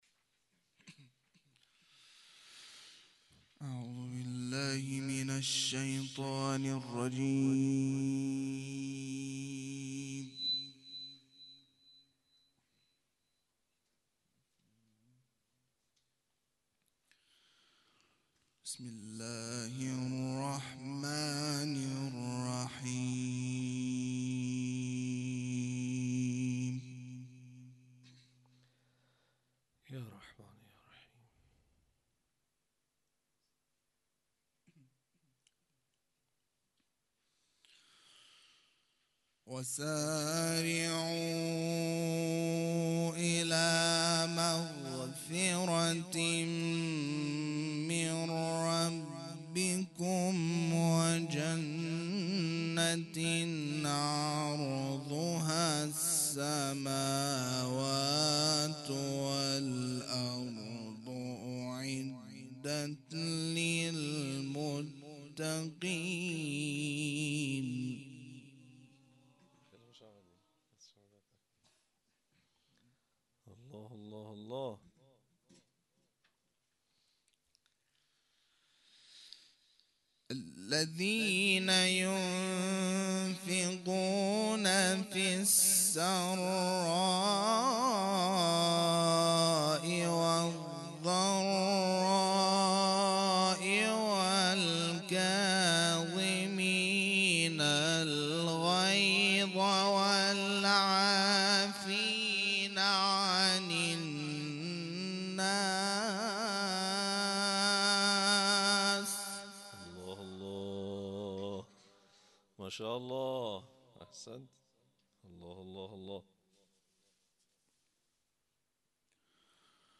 صوت چهاردهمین کرسی تلاوت قرآن کریم